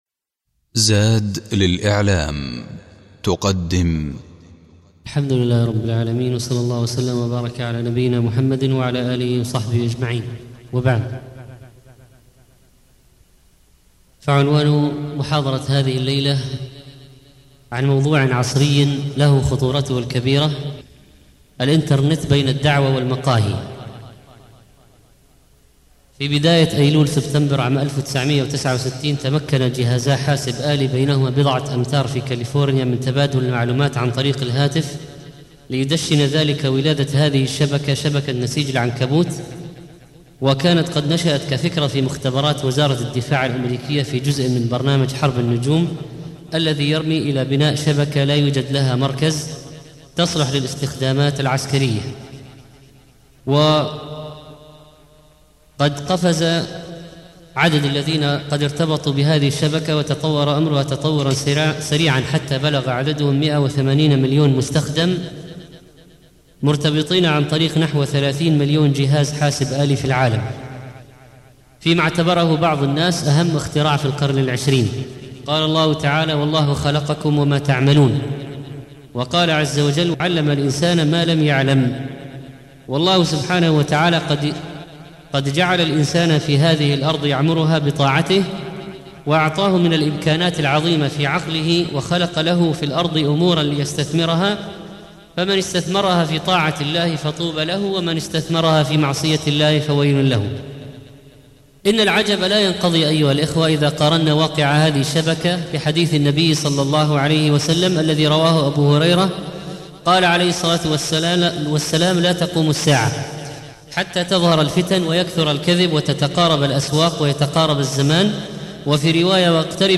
الموقع الرسمي للشيخ محمد صالح المنجد يحوي جميع الدروس العلمية والمحاضرات والخطب والبرامج التلفزيونية للشيخ